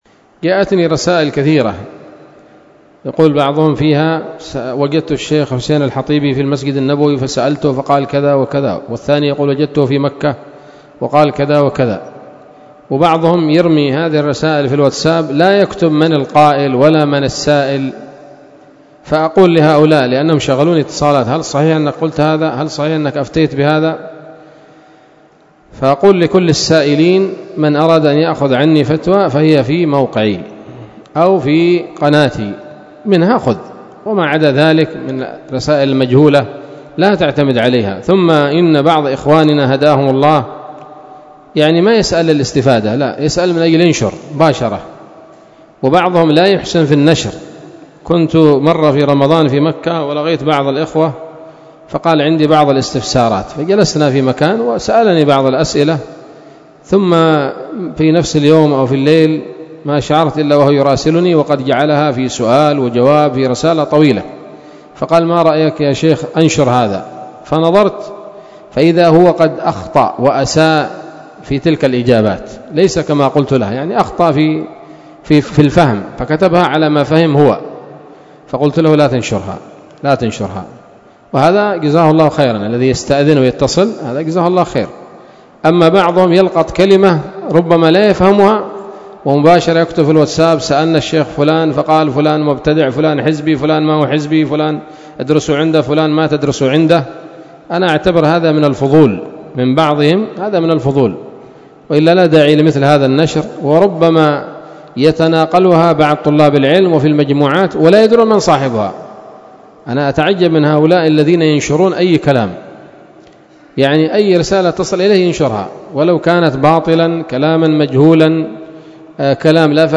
كلمة قيمة بعنوان: ((لا تنشر كل ما تسمع )) ظهر الأربعاء 4 جمادى الأولى 1446هـ، بدار الحديث السلفية بصلاح الدين